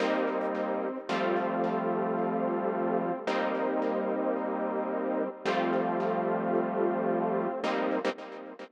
30 Synth PT3.wav